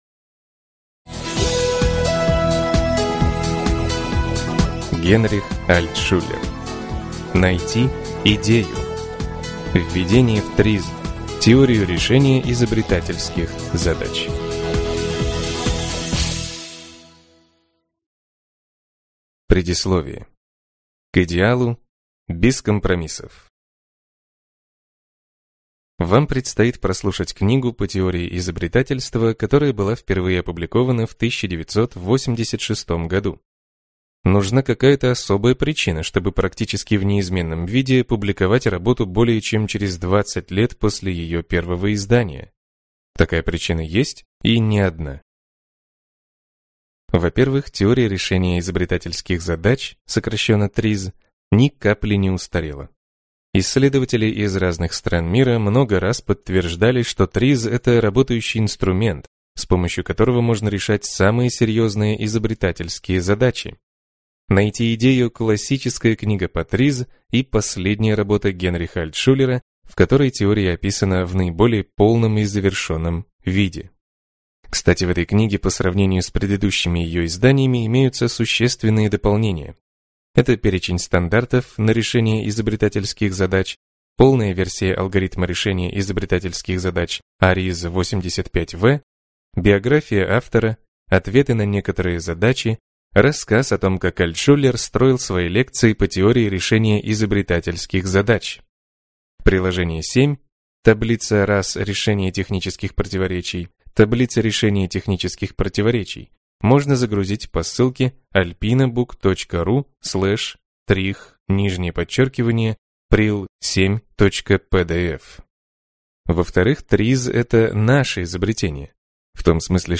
Аудиокнига Найти идею. Введение в ТРИЗ – теорию решения изобретательских задач | Библиотека аудиокниг